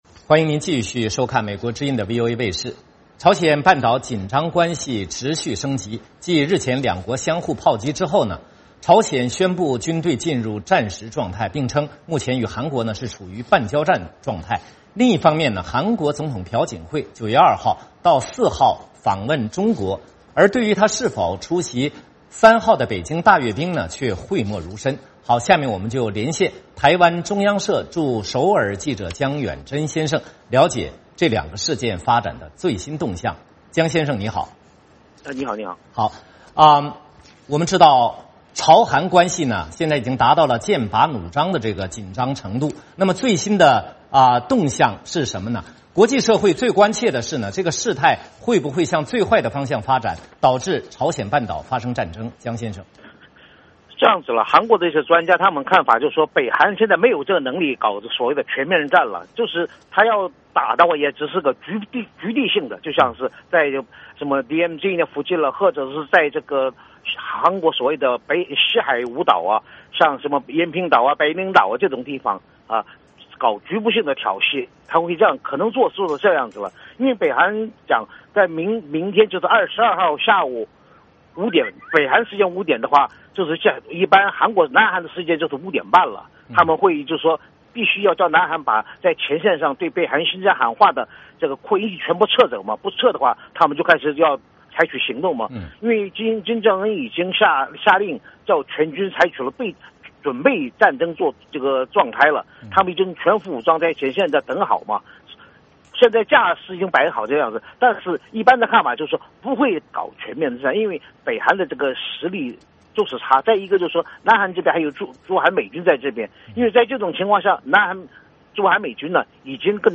VOA连线：朝鲜宣布与韩国进入“半交战”状态